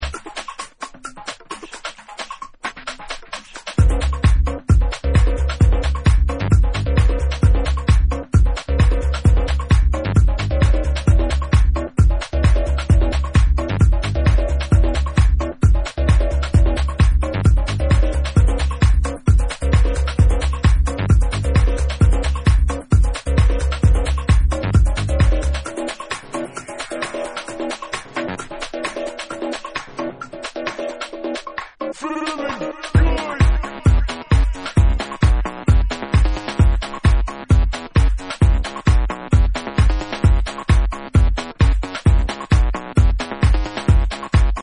Techhouse / minimal techbomb for the dancefloor
Techno